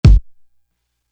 Tick Tock Kick.wav